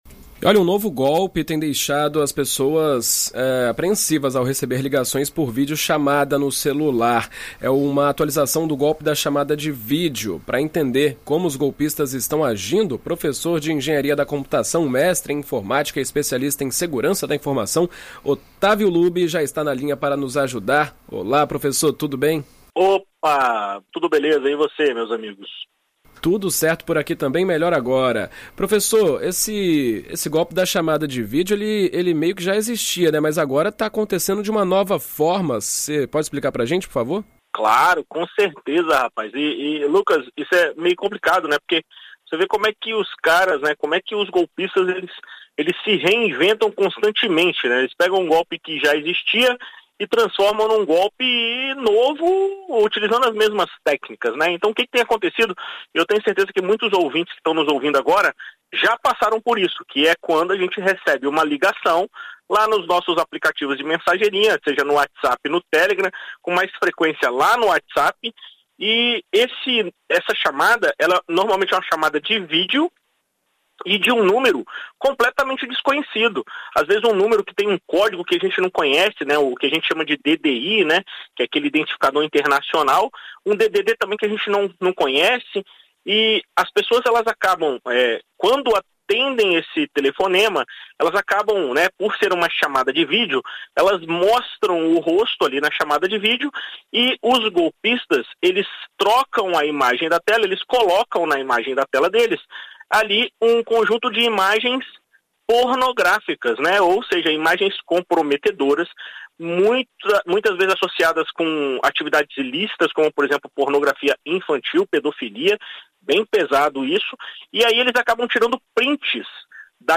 Ouça o comentário completo!